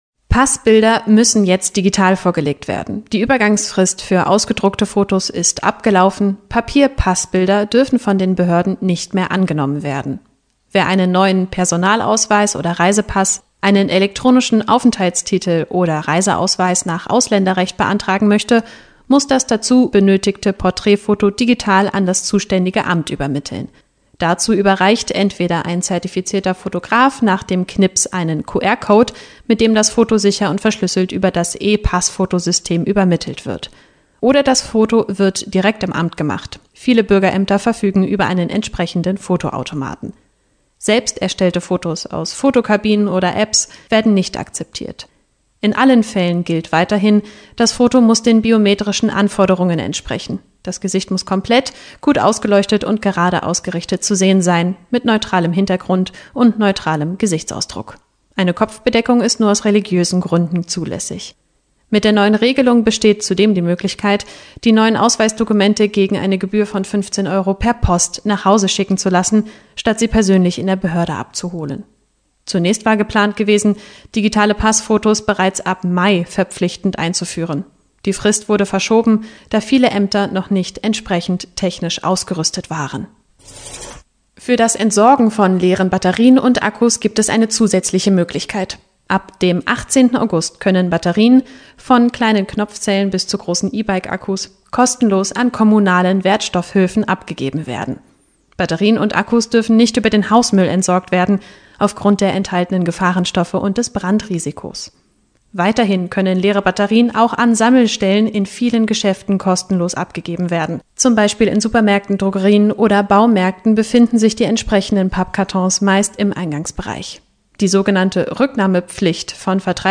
Aufsager-Neu-ab-August.mp3